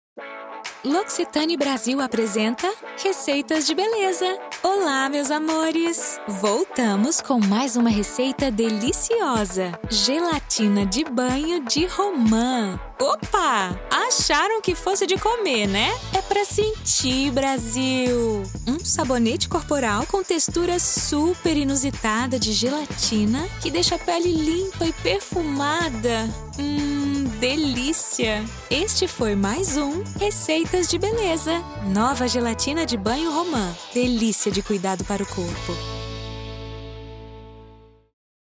Natural, Reliable, Friendly, Soft, Corporate